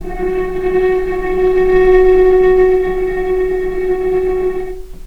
vc-F#4-pp.AIF